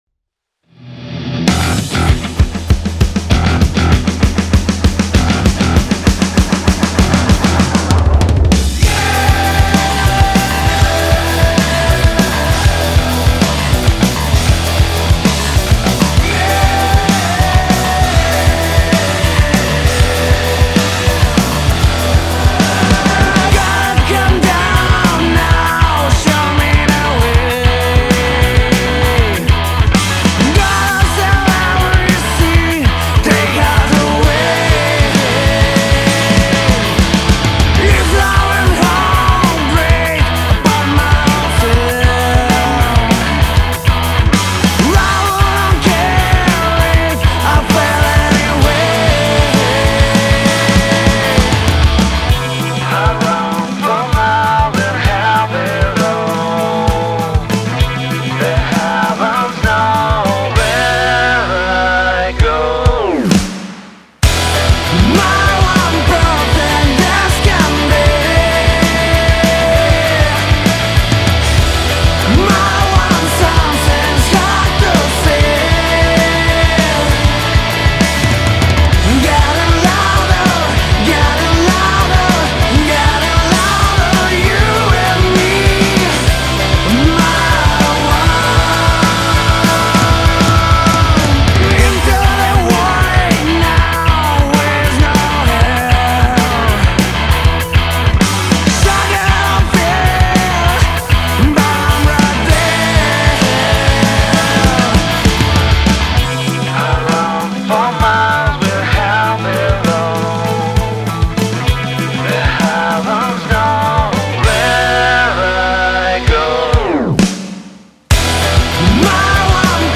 Rock,песня.
Стреляет/торчит таки рабочий немного в лицо, в моно слышно, аж громче вокала.